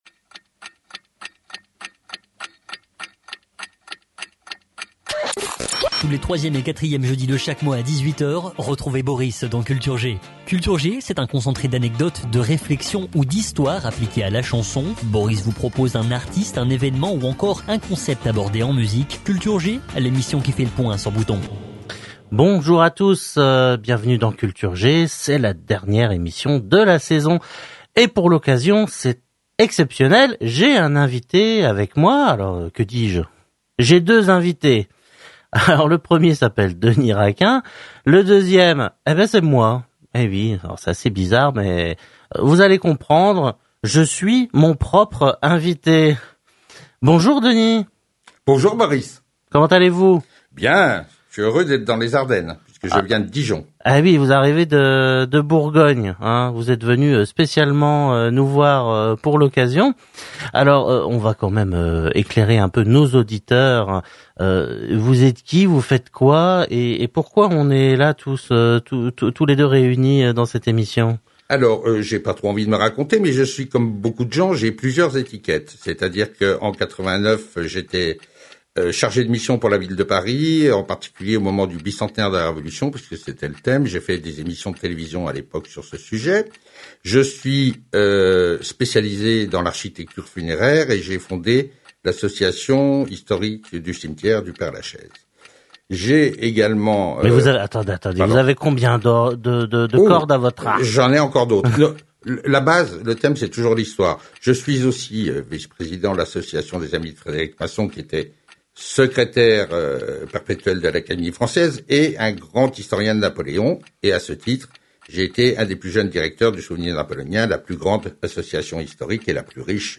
En juillet 2021, nous étions dans les Ardennes où nous avons enregistré une émission Radio Bouton, radio associative et scolaire à Boutancourt.